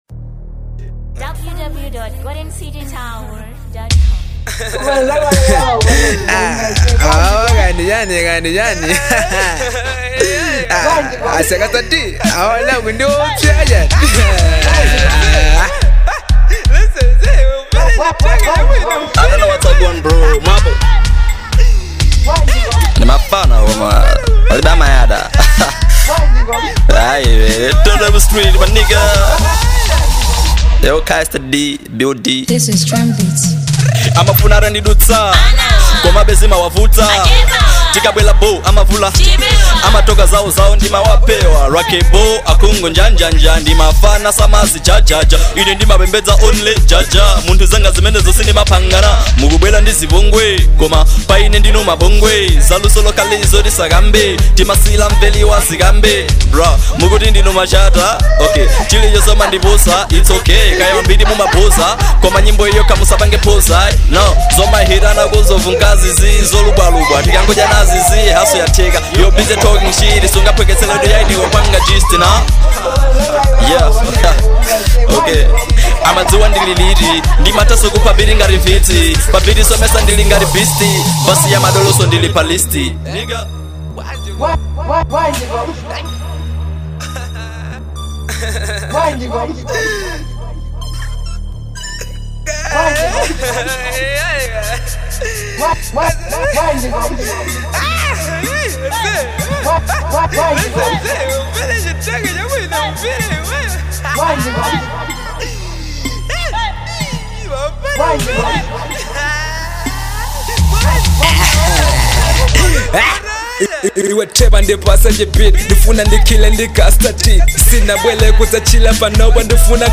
2. Amapiano